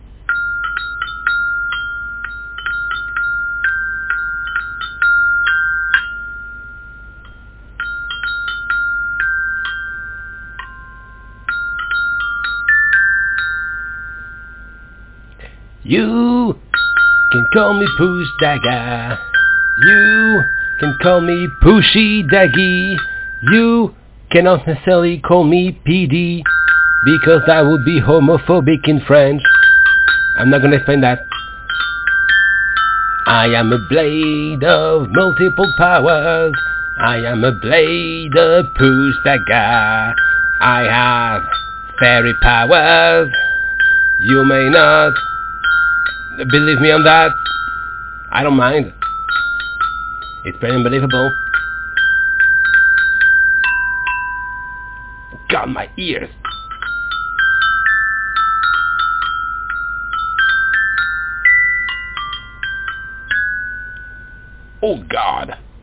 • The instrument is a xylophone which make a very high sound in real life.